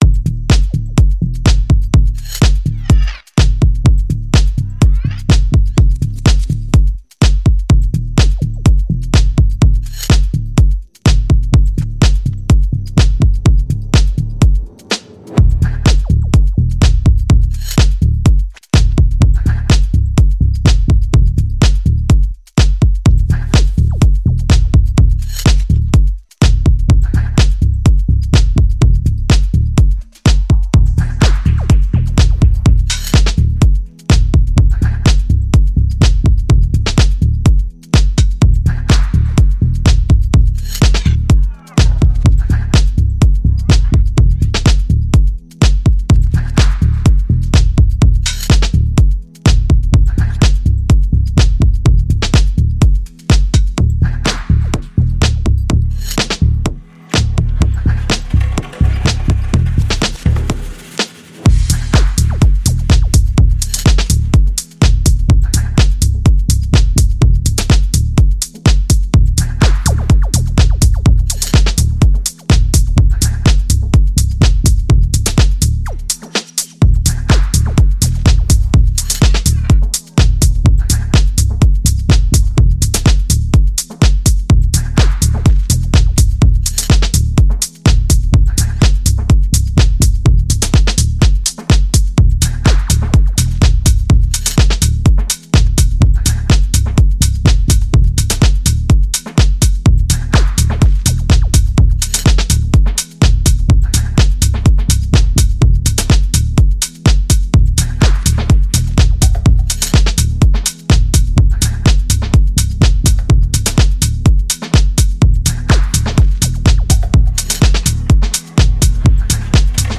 introspectiva viagem rítmica